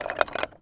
metal_small_movement_16.wav